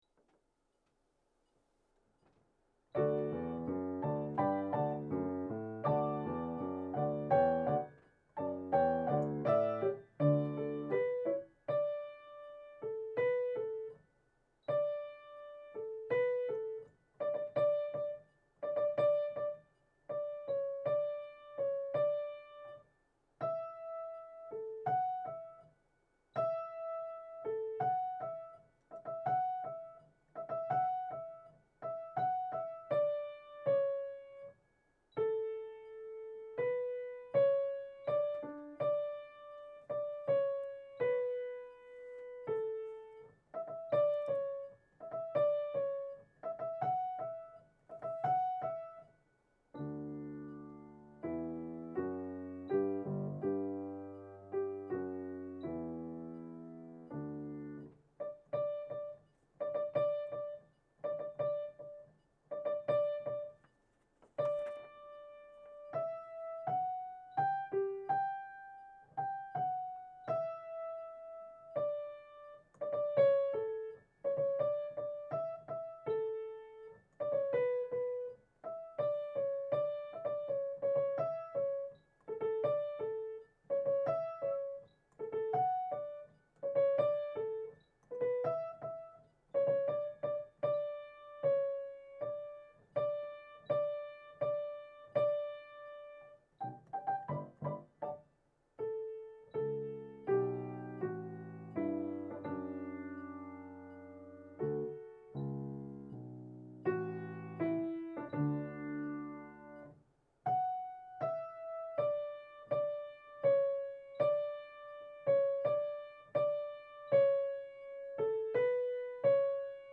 ハレルヤ_ソプラノ.MP3